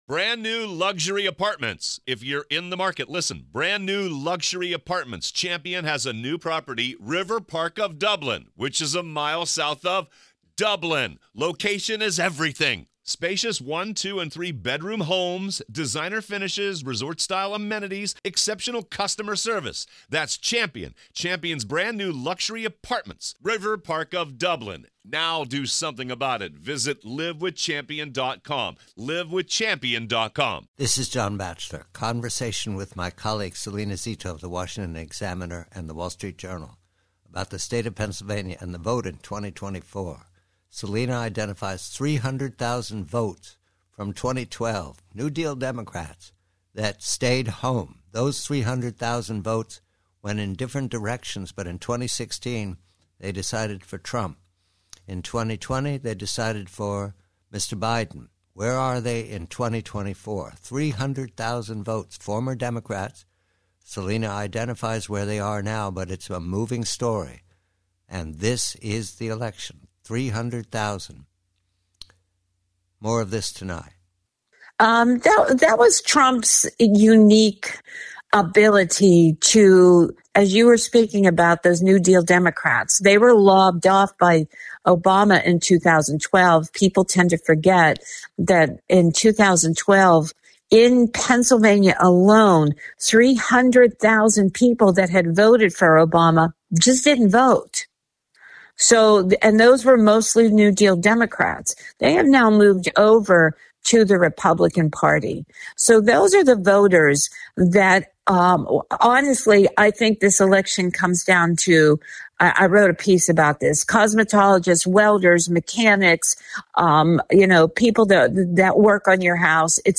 Preview: Conversation with colleague Salena Zito of the Washington Examiner regarding the 300,000 votes in Pennsylvania that may well determine the state and the Election for the White House in 2024.